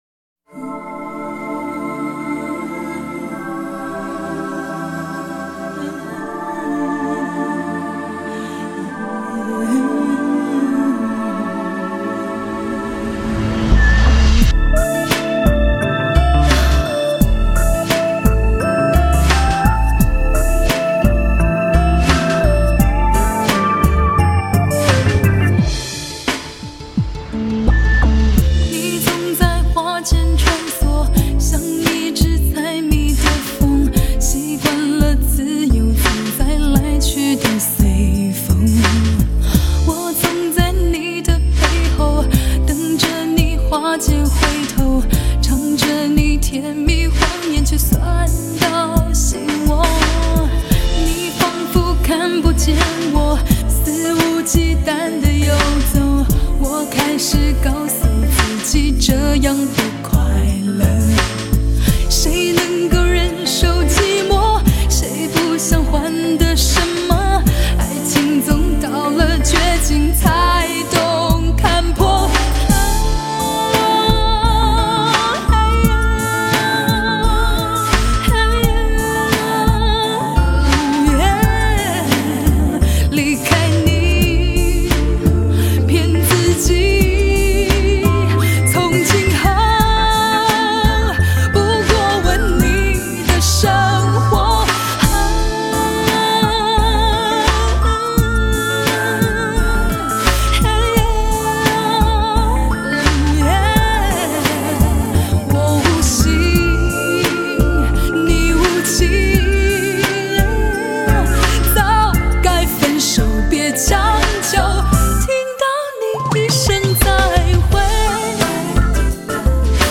她的声音柔柔地熨过
最精采的生活情歌